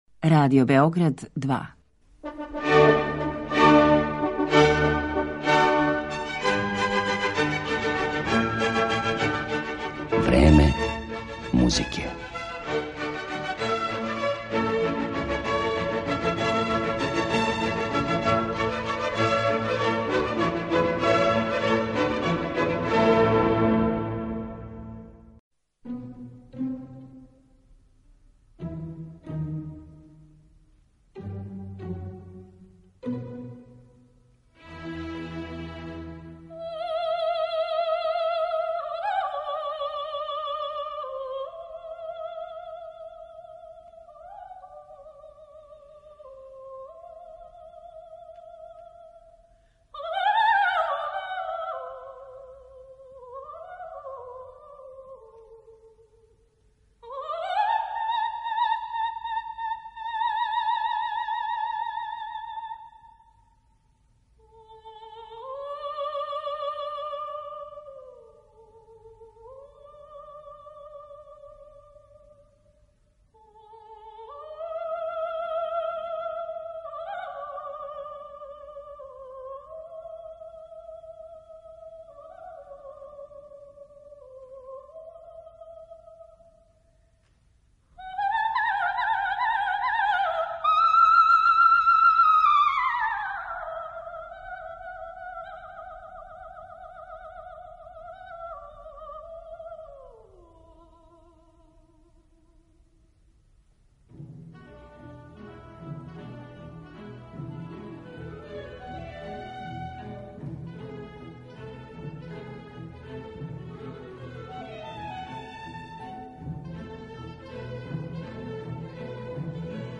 Поред занимљивости из њеног живота и каријере, слушаћете и неке од најлепших интерпретација које су остале забележене на носачима звука.